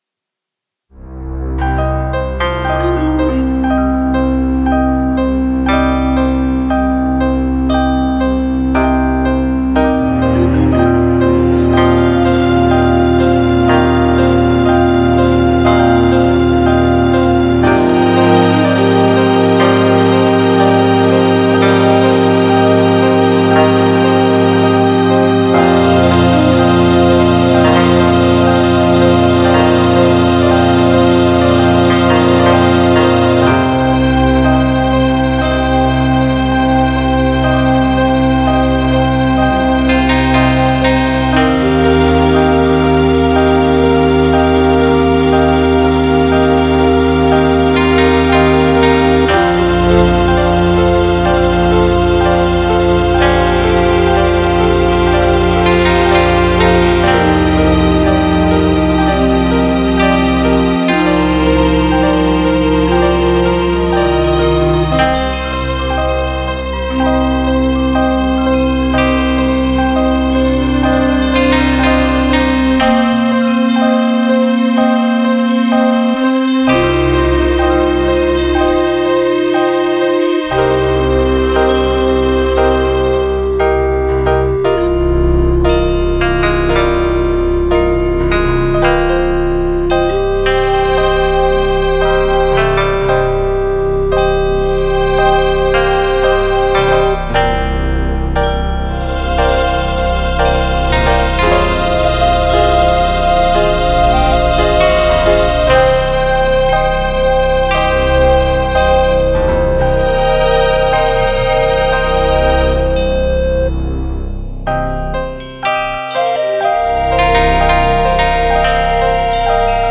asian style tune